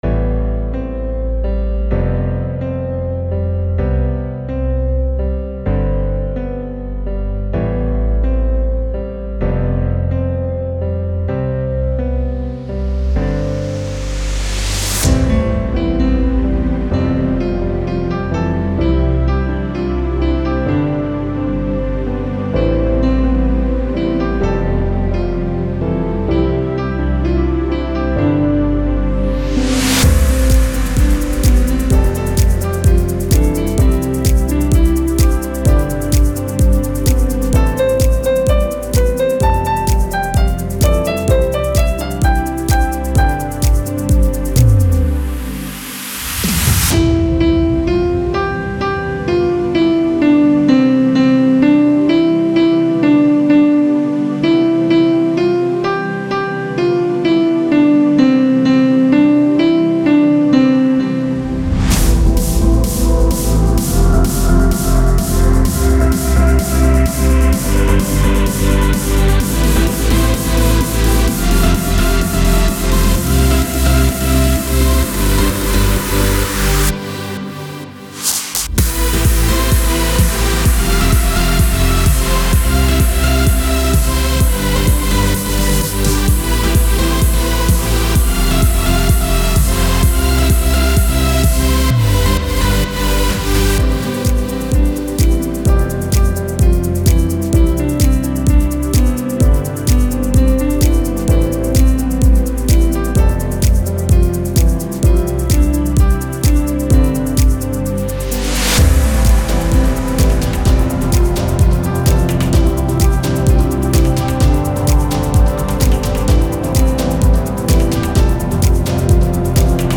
乐声悠扬，共抗疫情 原创歌曲《上农，我们在一起》